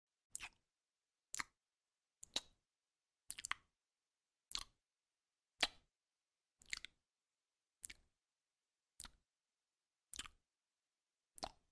Tiếng Son môi, Đánh son, Tô son, trang điểm….
Thể loại: Tiếng động
Description: Tiếng son môi khi thoa, đánh hay tô môi, tiếng thoa son, bôi son, xoa son, phủ màu, đánh môi, tô môi, trang điểm làm môi quyến rũ tạo cảm giác sống động. Hiệu ứng âm thanh, sfx tiếng vỏ son, tiếng rít mịn, tiếng liếm môi nhẹ thường được dùng để tăng cảm xúc...
tieng-son-moi-danh-son-to-son-trang-diem-www_tiengdong_com.mp3